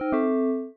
Add sound effects!
timeup.ogg